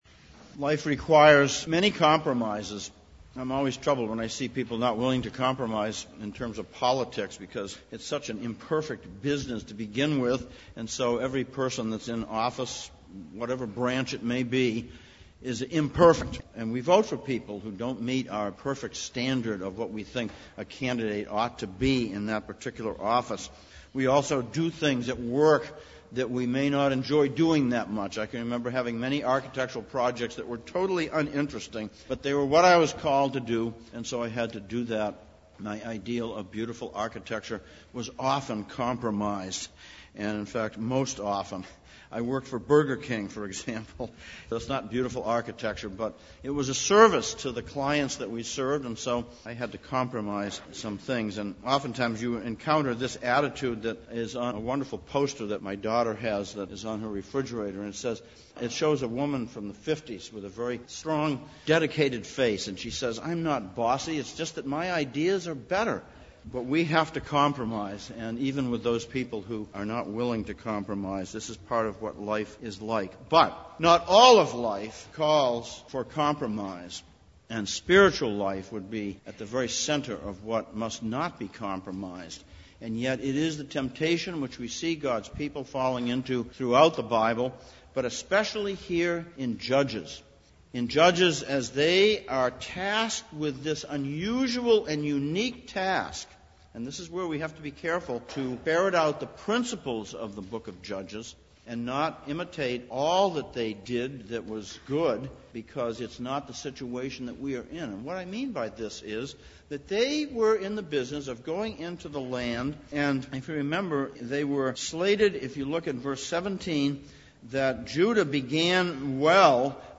Judges Passage: Judges 1:16-2:6, Hebrews 12:1-17 Service Type: Sunday Morning « The Regulative Principle